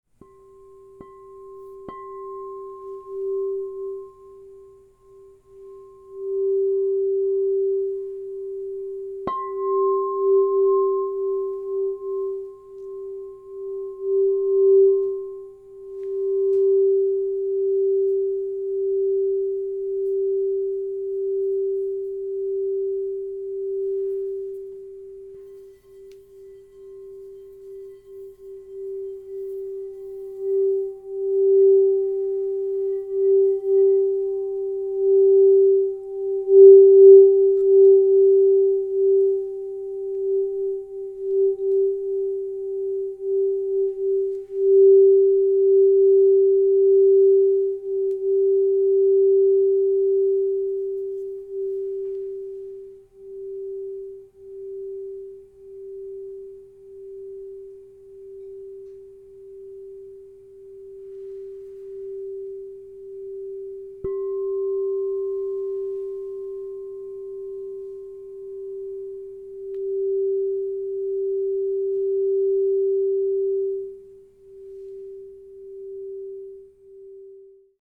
Androgynous Indium Practitioner 7″ G -15 Crystal Tones Singing Bowl
At 7 inches in size, in the note of G, this instrument resonates with the throat chakra, empowering authentic expression, truth, and alignment between voice and heart. Its tone is steady, luminous, and clear, making it a reliable ally for both solo work and group sound baths.
432Hz (-)